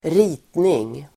Uttal: [²r'i:tning]